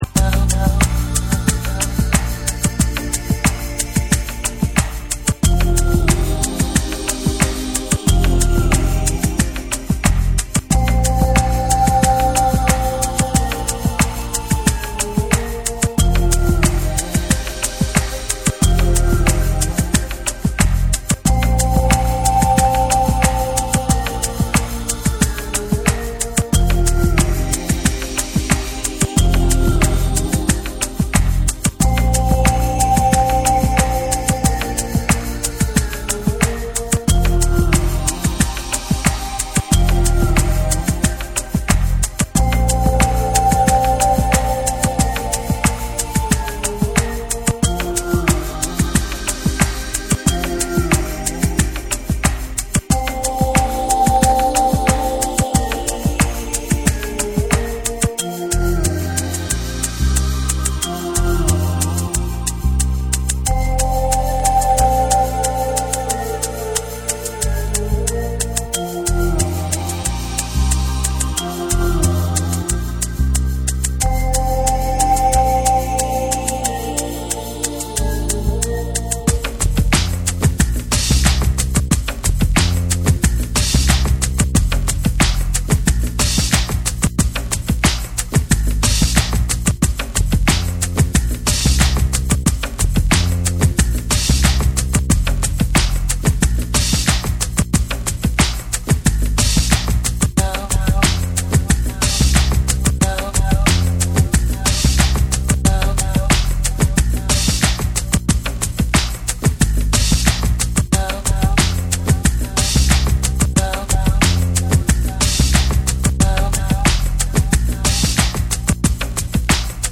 哀愁漂うメロウ・ダウンビート
BREAKBEATS / ORGANIC GROOVE